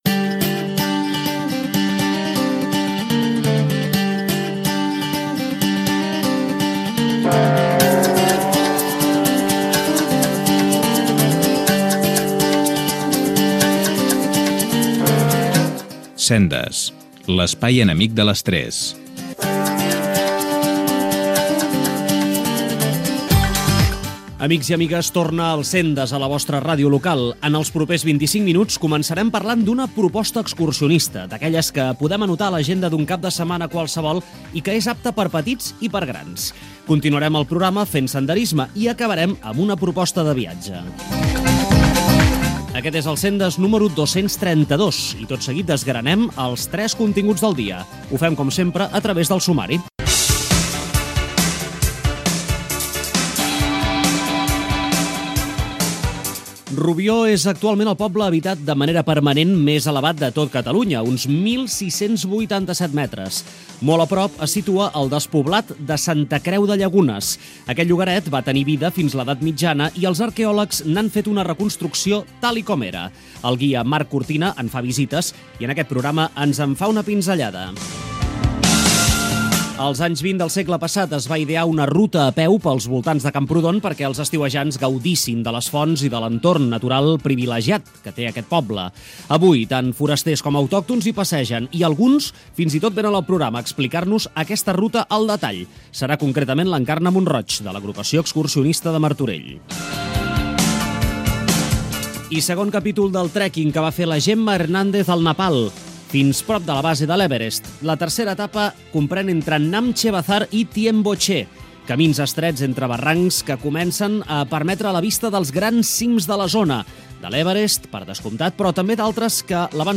Careta, inici i sumari del programa dedicat al senderisme
Divulgació
FM